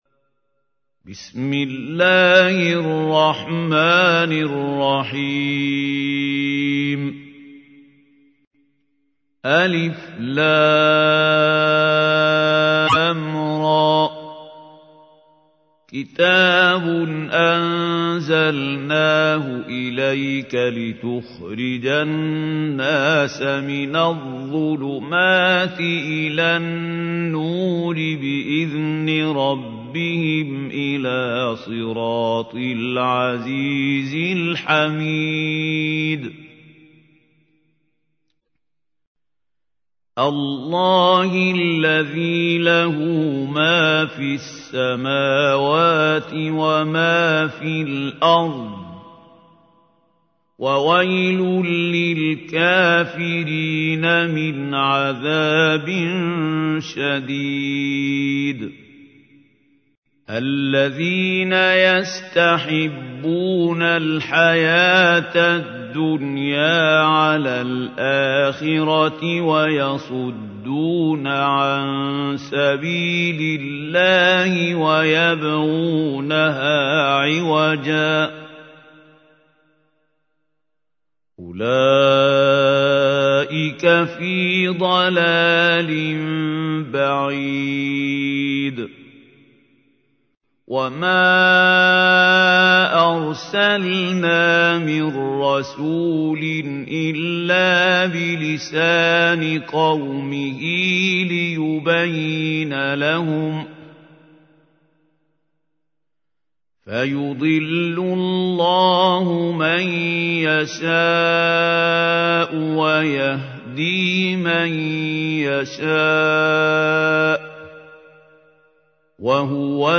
تحميل : 14. سورة إبراهيم / القارئ محمود خليل الحصري / القرآن الكريم / موقع يا حسين